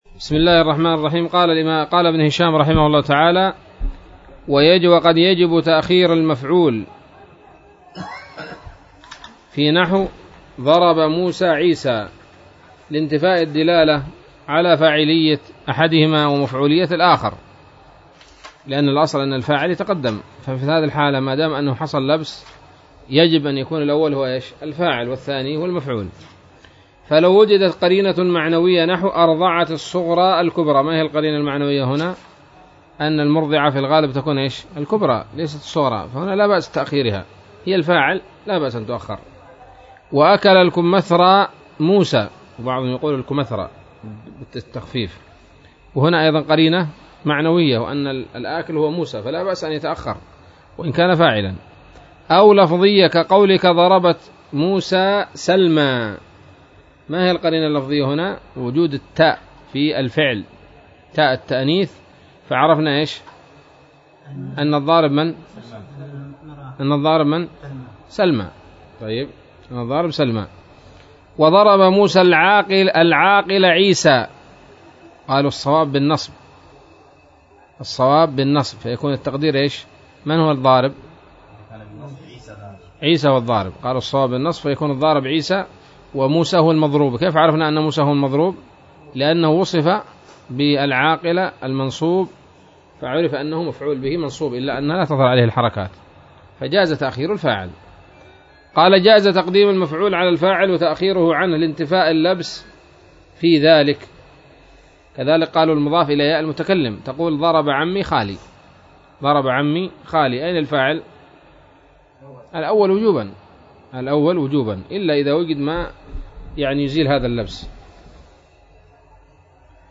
الدرس السادس والسبعون من شرح قطر الندى وبل الصدى